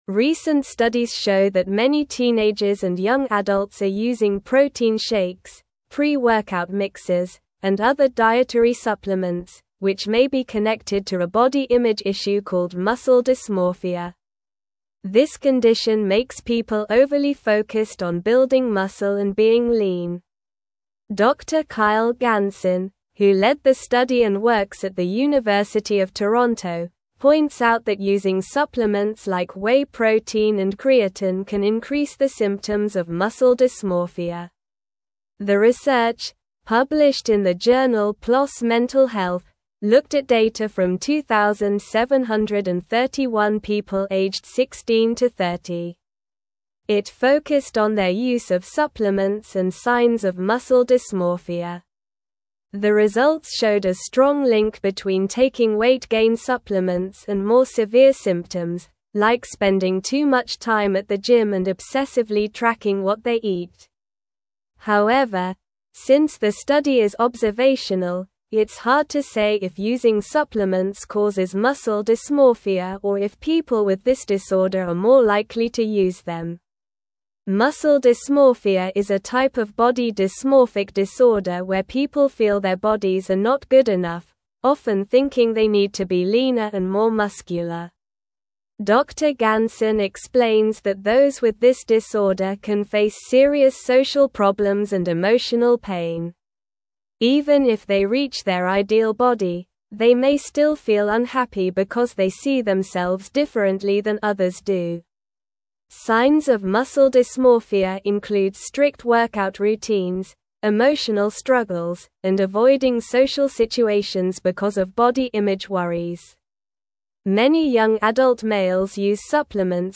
Slow
English-Newsroom-Upper-Intermediate-SLOW-Reading-Link-Between-Supplements-and-Muscle-Dysmorphia-in-Youth.mp3